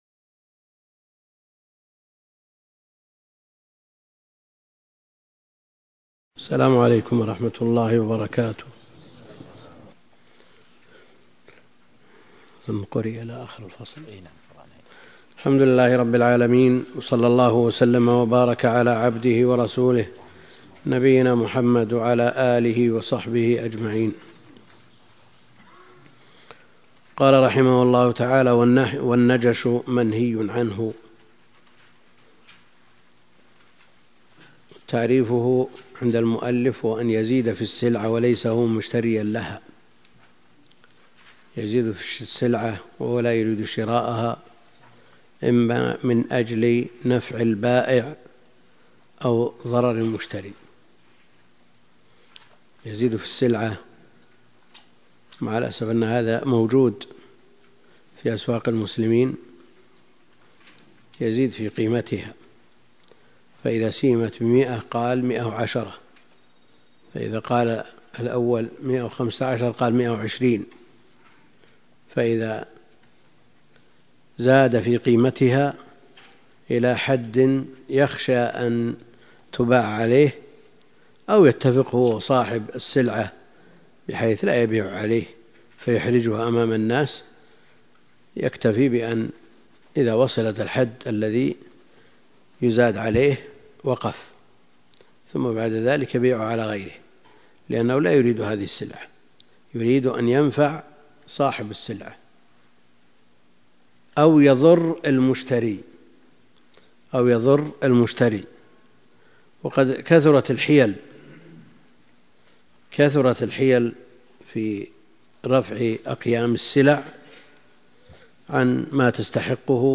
شرح مختصر الخرقي كتاب البيوع (110 9) - الدكتور عبد الكريم الخضير